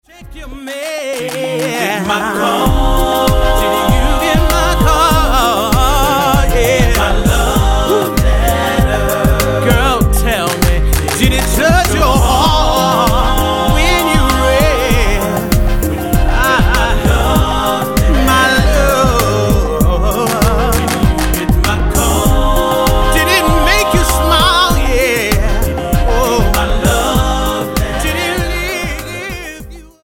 NOTE: Background Tracks 1 Thru 9